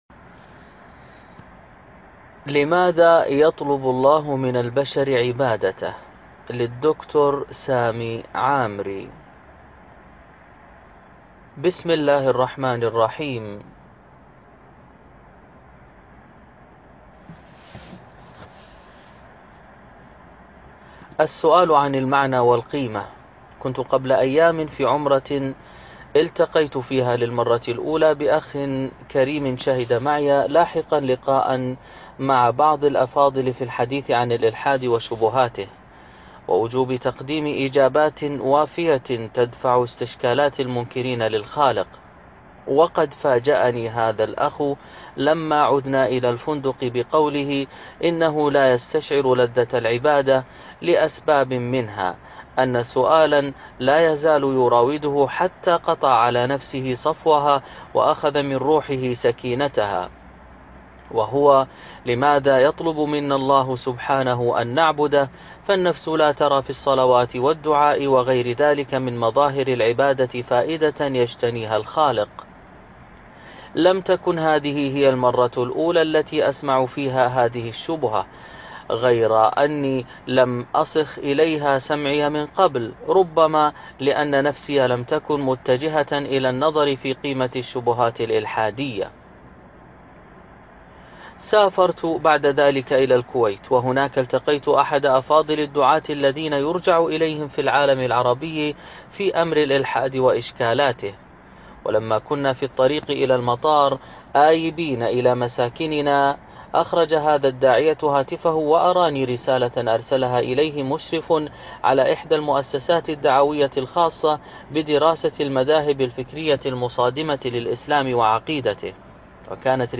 الكتاب المسموع (كاملا) لماذا يطلب الله من البشر عبادته؟